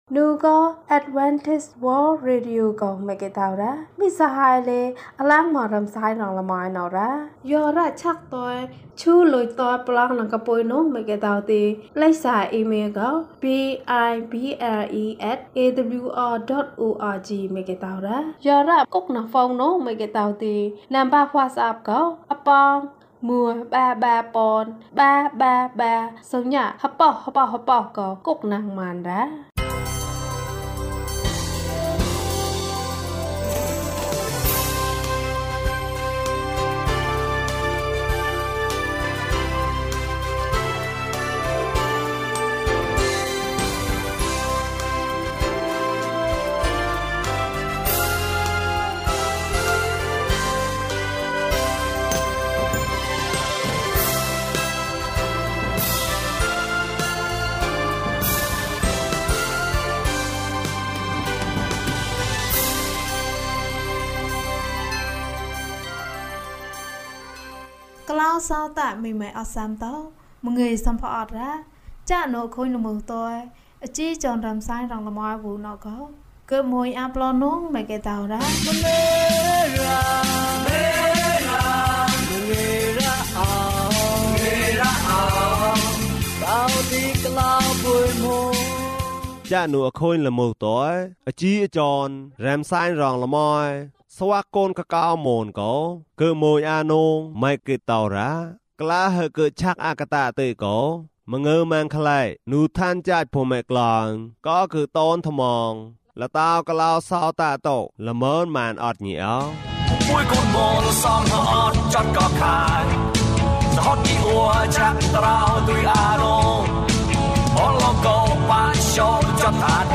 ဒုတိယအကြိမ်ကြွလာခြင်း။ ကျန်းမာခြင်းအကြောင်းအရာ။ ဓမ္မသီချင်း။ တရားဒေသနာ။